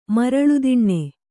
♪ maraḷu diṇṇe